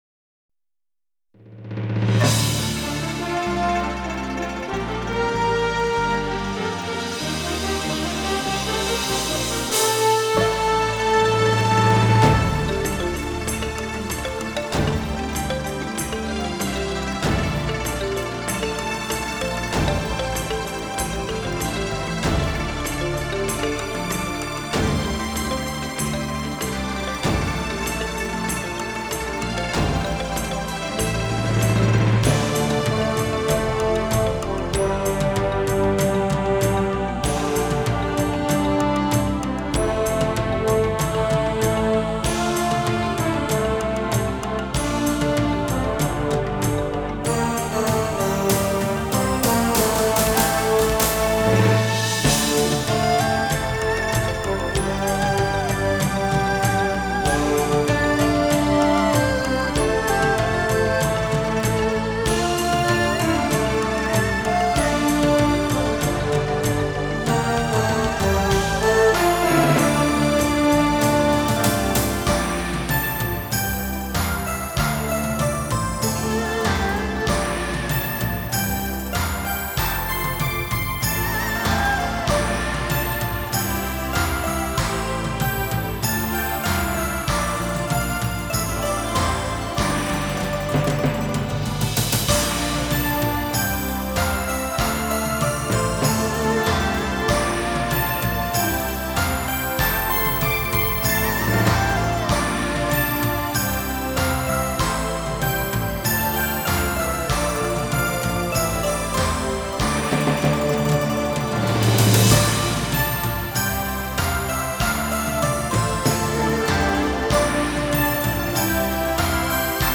原声大碟.-.